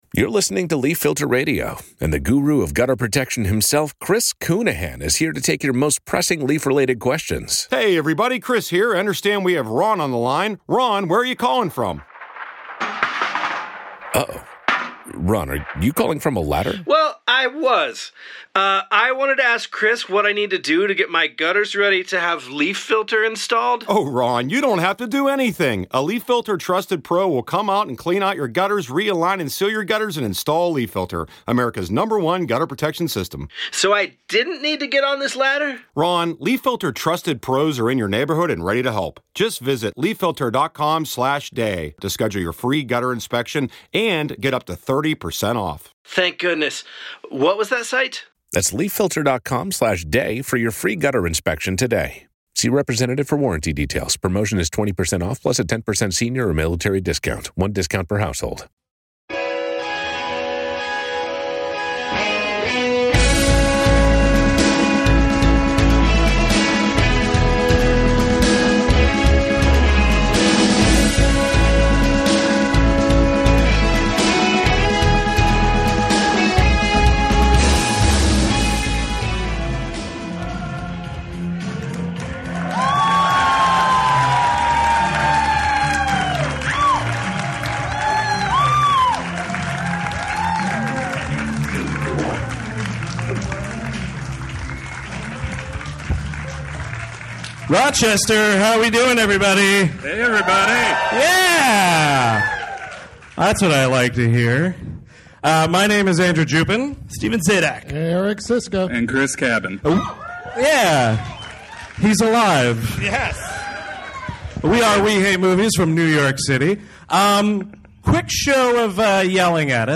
Recorded live in Rochester, July 16th, 2016 On this episode, the gang treks out to the Little Theatre to chat about the completely worthless sequel, Teenage Mutant Ninja Turtles III! How could they cut the budget for these turtle puppets?